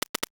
NOTIFICATION_Subtle_08_mono.wav